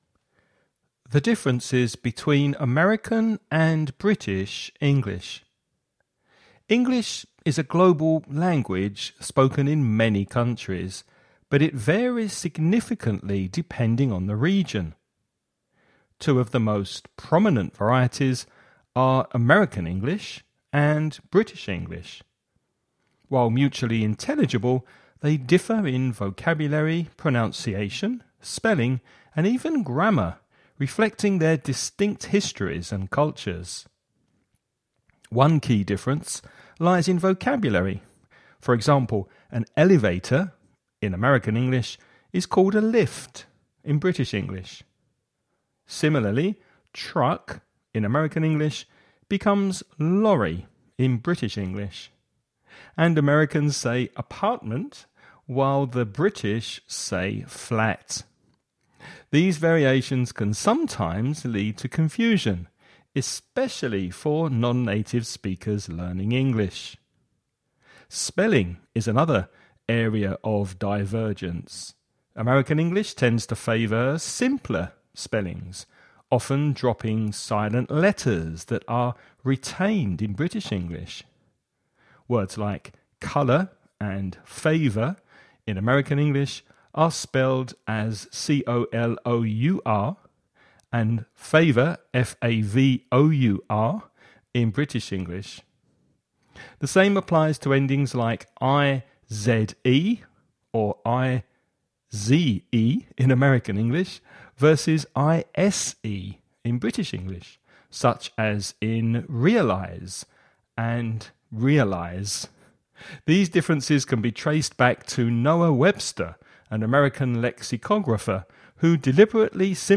Listening Practice
You’re going to listen to a man talking about the differences between American and British English.